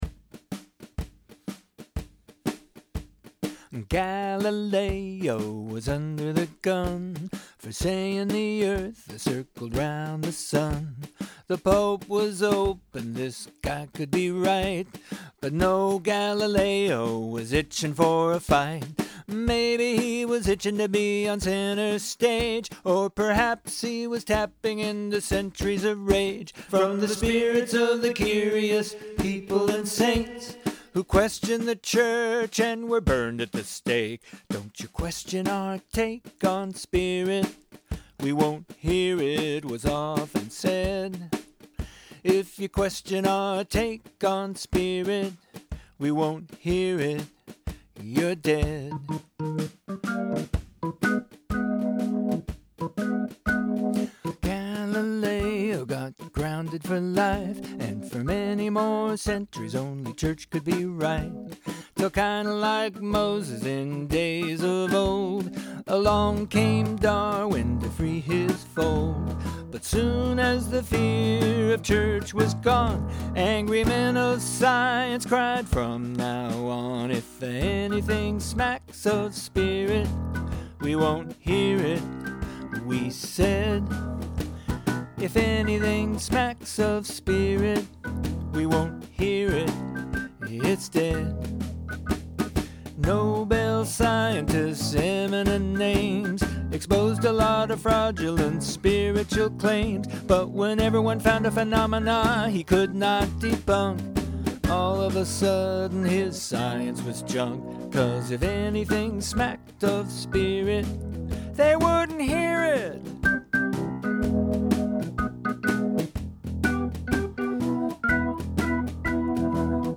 musical take